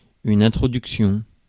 Ääntäminen
Synonyymit exorde Ääntäminen France: IPA: [ɛ̃t.ʁɔ.dyk.sjɔ̃] Haettu sana löytyi näillä lähdekielillä: ranska Käännöksiä ei löytynyt valitulle kohdekielelle.